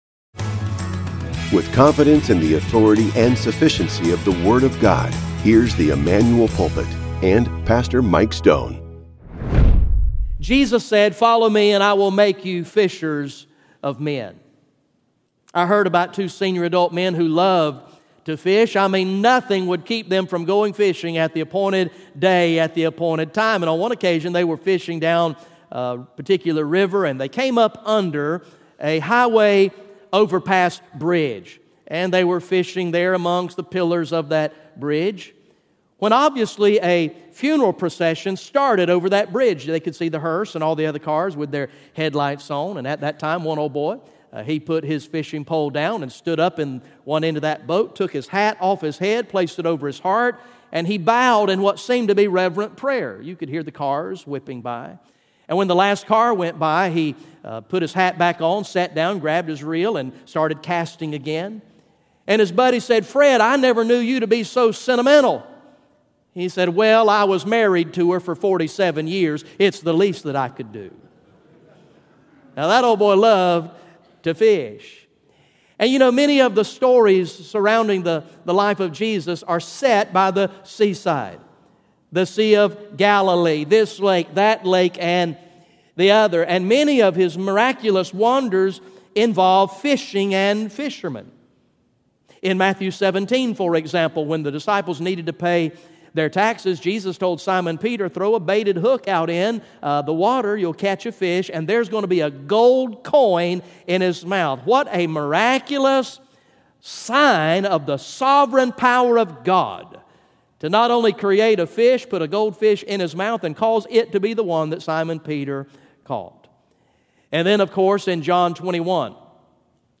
GA Message #12 from the sermon series entitled “King of Kings